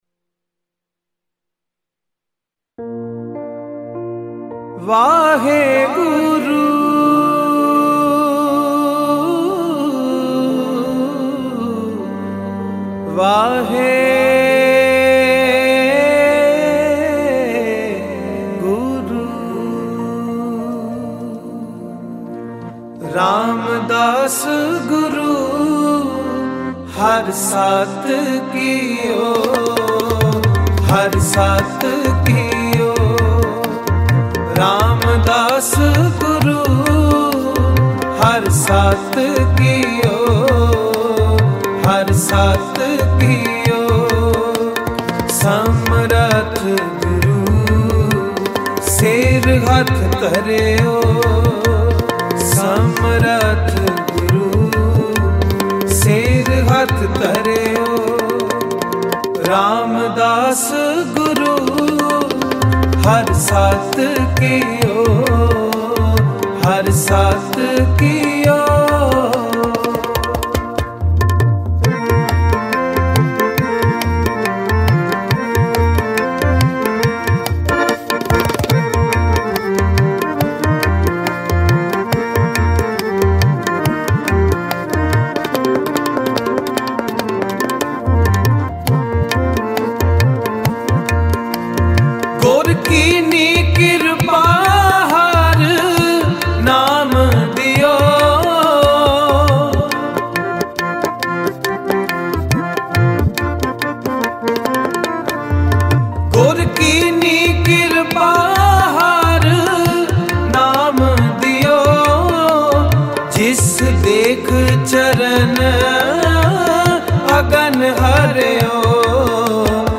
Mp3 Files / Gurbani Kirtan / 2025 Shabad Kirtan /